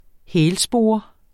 Udtale [ ˈhεːl- ]